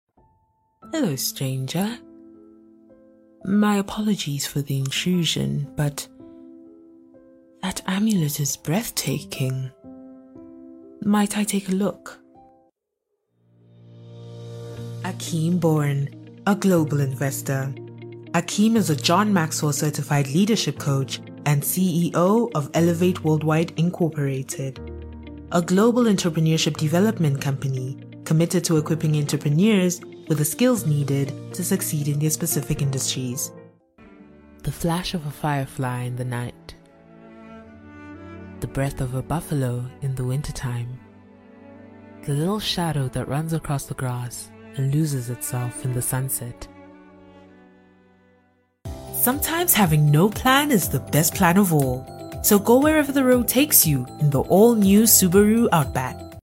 Vocal Styles:
caring, compassionate, maternal, nurturing
My demo reels